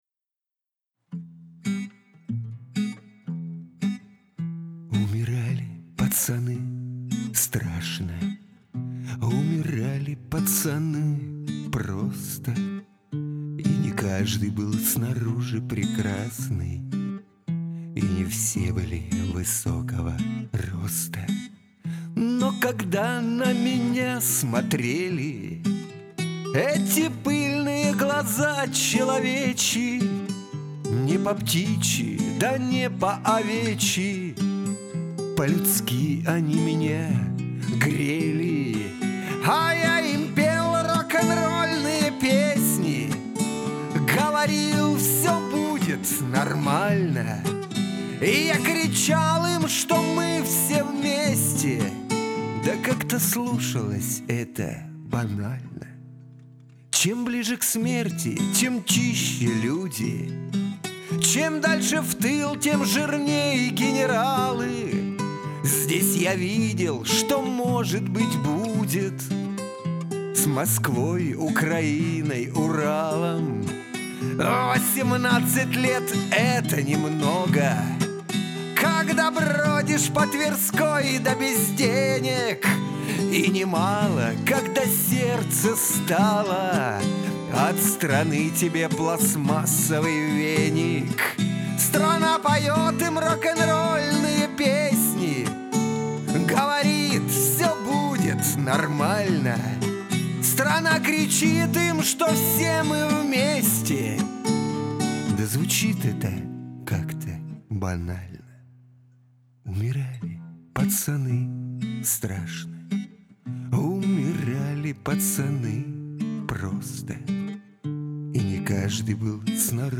Как же приятно послушать песни под гитару!